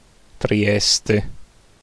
ascolta, AFI: /triˈɛste/[4]; Trieste in dialetto triestino; in tedesco e in friulano Triest; in sloveno Trst[5]) è un comune italiano di 198 679 abitanti[1], capoluogo della regione Friuli-Venezia Giulia, affacciato sull'omonimo golfo fra la penisola italiana e l'Istria.